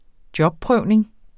Udtale [ -ˌpʁœwˀneŋ ]